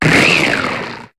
Cri d'Opermine dans Pokémon HOME.